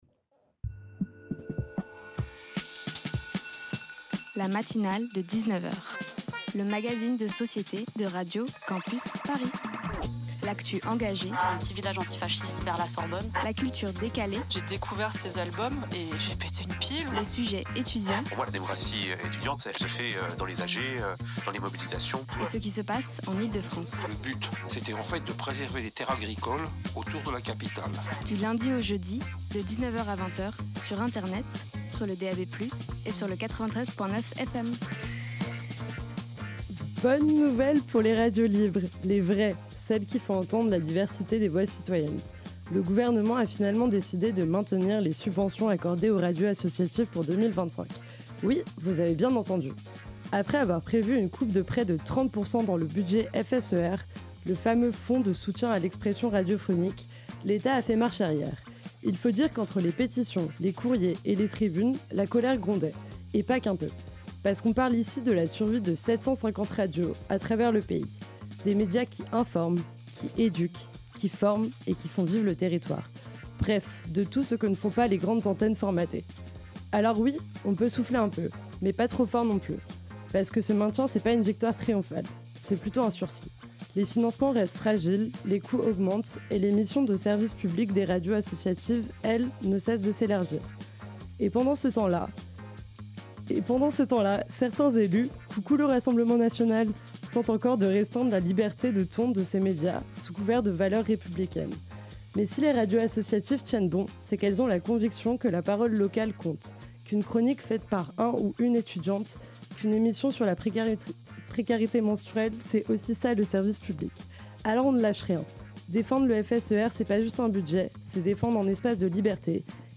La Matinale de 19 heures tags local vie étudiante société vie associative Partager Partager × Radio Campus Paris La Matinale de 19 heures Lundi - Mardi - Mercredi - Jeudi ... à 19h (!) Du lundi au jeudi entre 19h et 20h, la fine équipe de La Matinale décortique les sujets qui font l'actualité politique, internationale, sportive, culturelle, botanique, anthropomorphique, anticonstitutionnelle... Tous les soirs, des reportages pas chiants, des chroniques épiques et des interviews garanties sans conservateur viendront ponctuer cette heure où l’ennui subit le même sort que Bonaparte : le bannissement.